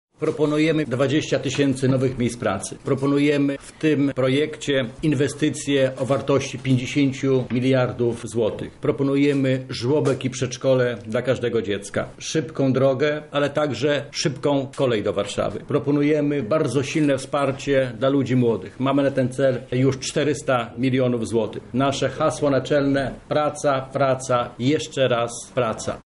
– mówi Krzysztof Grabczuk, wicemarszałek województwa lubelskiego.